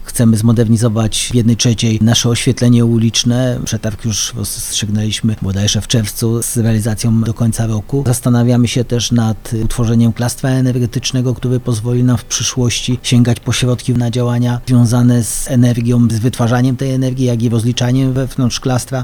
Ale to nie jedyne inicjatywy, które mają spowodować mniejsze zużycie energii, mówi burmistrz Szydłowca Artur Ludew: